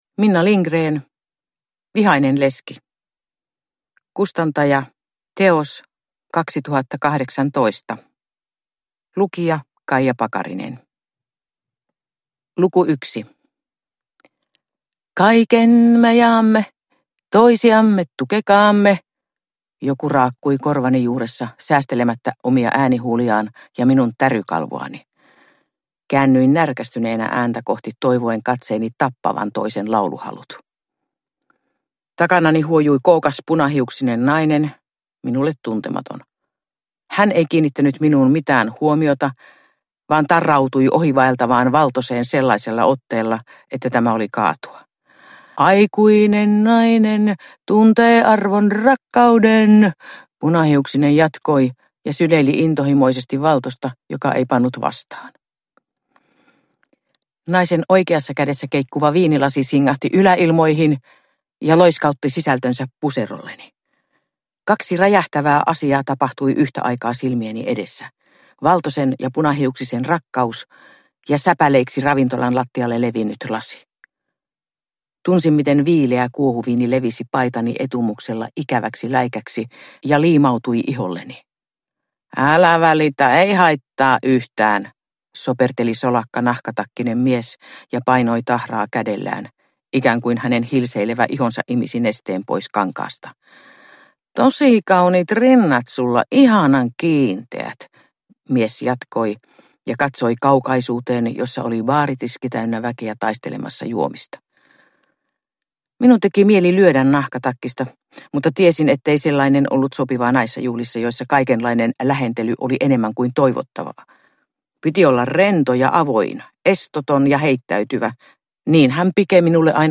Vihainen leski – Ljudbok